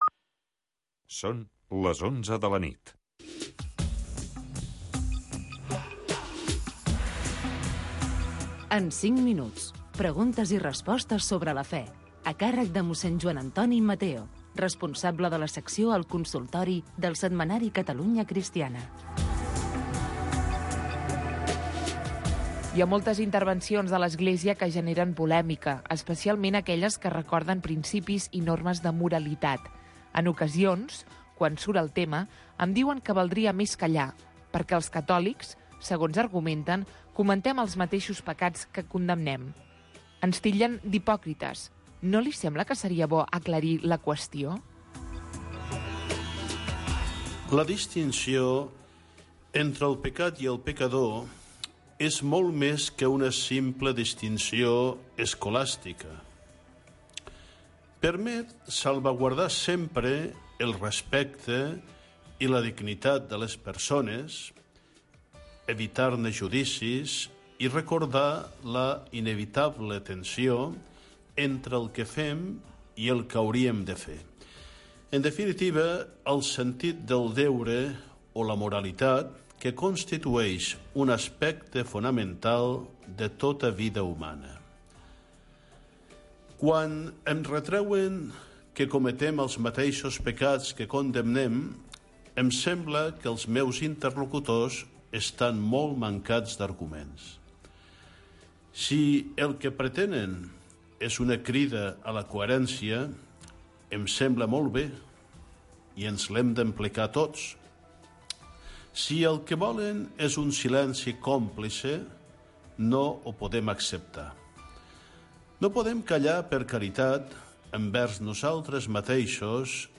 Espai on els oients poden fer consultes de tipus religiós